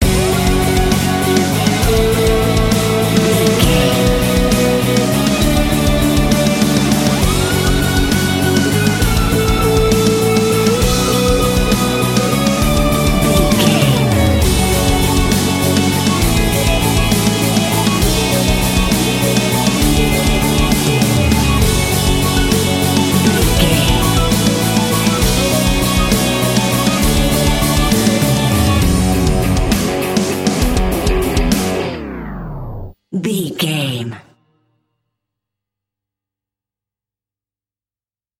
Aeolian/Minor
F#
drums
electric guitar
bass guitar
hard rock
lead guitar
aggressive
energetic
intense
nu metal
alternative metal